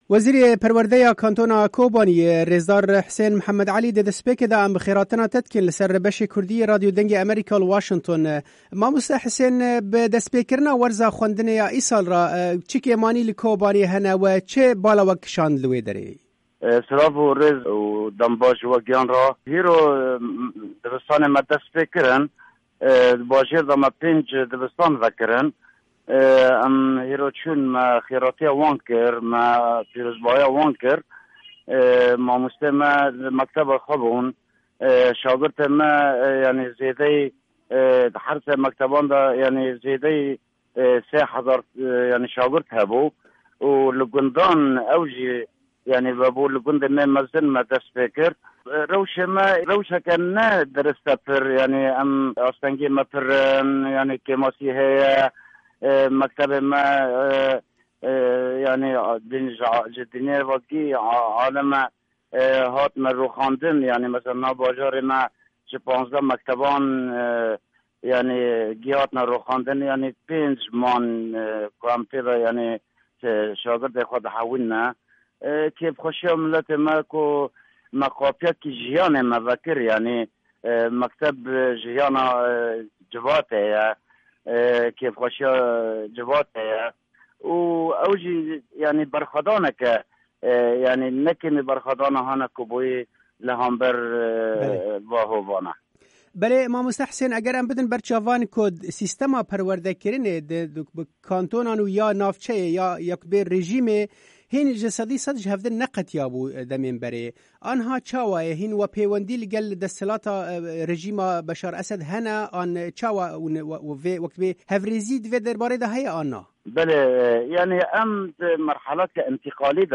Wezîrê perwerdeya Kantona Kobaniyê rêzdar Hisên Mihemed Elî, di hevpeyvînekê de li gel Dengê Amerîka rewşa niha ya perwedekirinê û destpêkirina werza xwendinê şîrove kir û got: